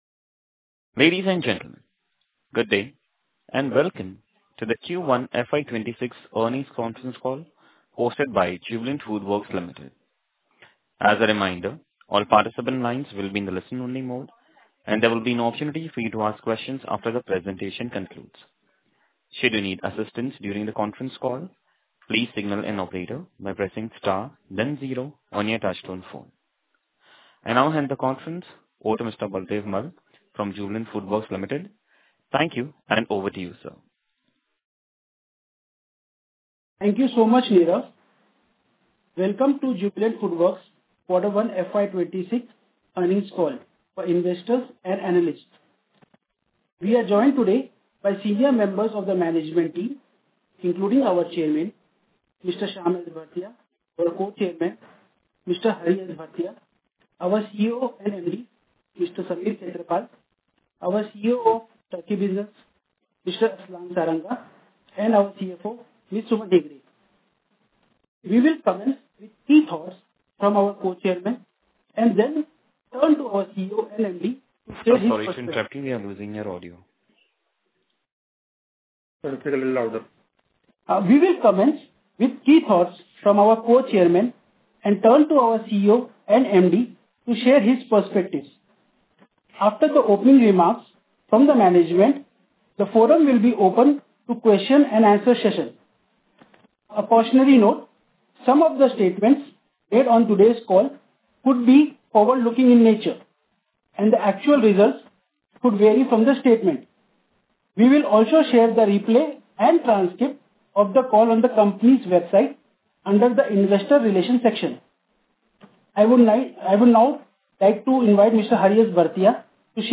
Jubilant Foodworks has released the audio recording of its Q1FY26 earnings call for analysts and investors, offering direct insights into the latest financial results and business updates.
1224foodfile-EarningsCallAudioRecording.mp3